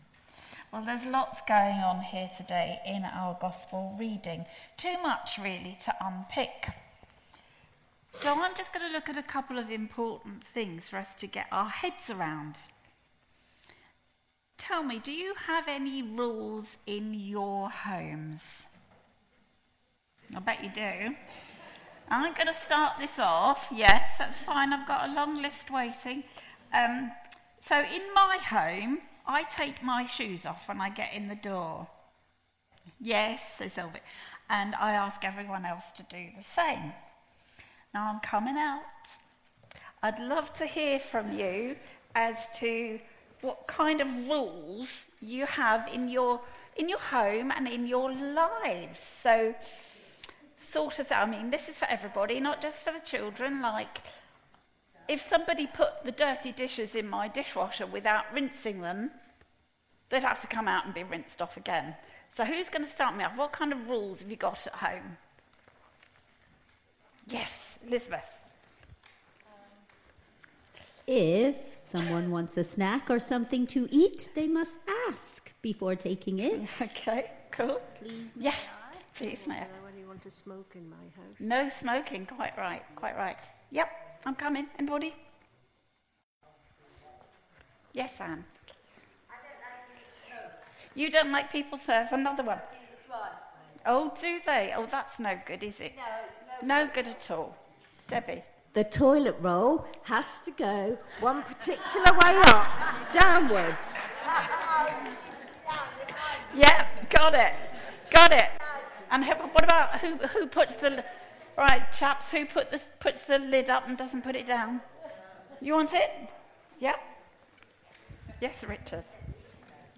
An audio version of the sermon is also available.
Passage: Psalm 139:1-18,23-24, Mark 2:23-3:6 Service Type: All Age Worship Today’s all age worship was led by our Lay Pioneer
06-02-sermon.mp3